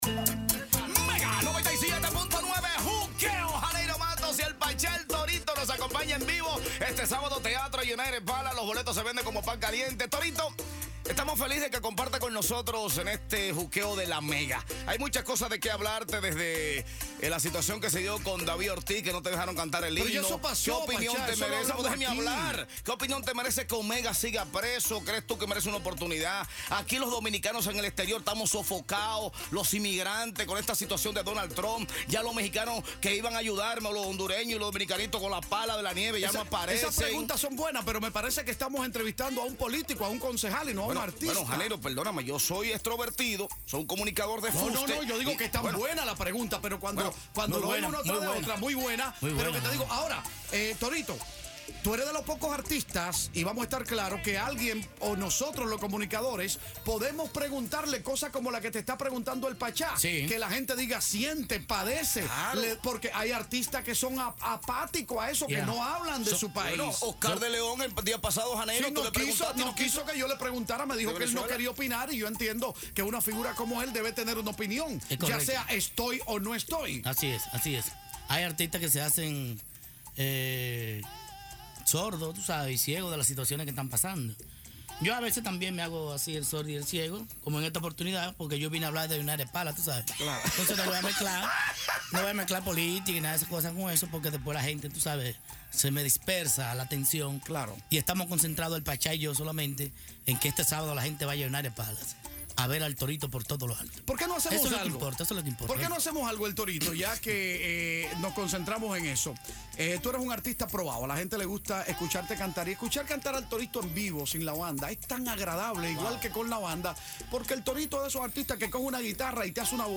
ENTREVISTA CON HECTOR ACOSTA EL TORITO PARTE 2 • La Mega 97.9